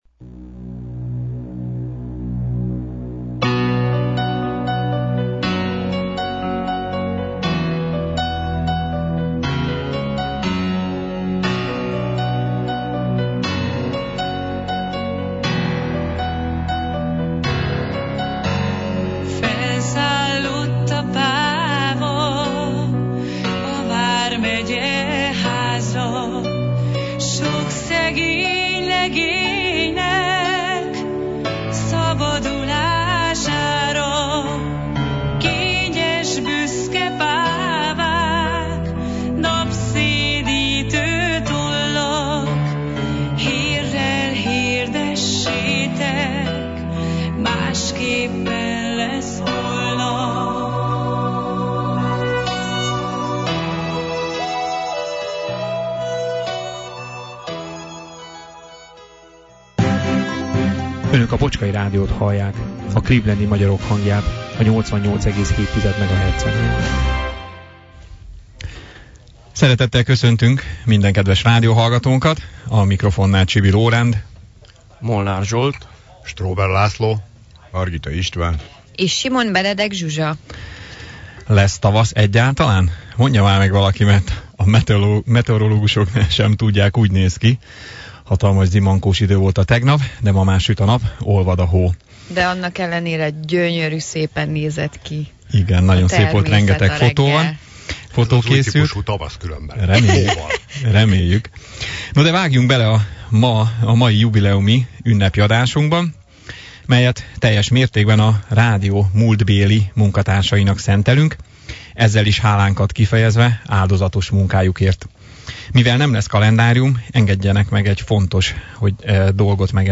Ünnepi fényben telt a március 30-i, vasárnapi rádióadásunk is, a megszokottól eltérő műsorral jelentkeztünk, meglepetésvendégekkel, régi hanganyagokkal és sok-sok felejthetetlen zeneszámmal. Ünnepi adásunk az elmúlt 30 évet ölelte át. Fölelevenítettük a múltat, megszólaltattunk régebbi műsorvezetőket, egy pár percig újra az éterben hallhatták akkori kedvenceiket. A technika vívmányainak köszönhetően a távolból is felcsengett egy-egy jól ismert hang.